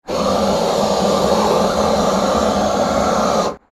Gas Burner Sound Effect
Hissing sound of gas and flame from a burner torch.
Gas-burner-sound-effect.mp3